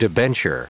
Prononciation du mot debenture en anglais (fichier audio)
Prononciation du mot : debenture